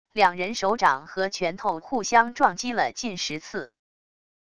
两人手掌和拳头互相撞击了近十次wav音频